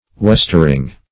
Meaning of westering. westering synonyms, pronunciation, spelling and more from Free Dictionary.
westering.mp3